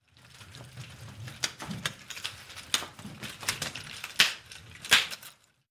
Шум взмахов нунчаками